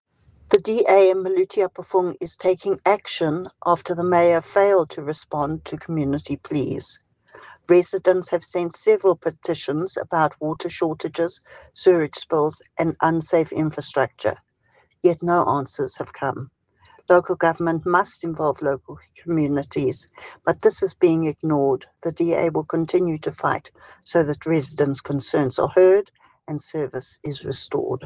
English soundbite by Cllr Alison Oates, Afrikaans soundbite by Cllr Eleanor Quinta and Sesotho soundbite by Cllr Ana Motaung.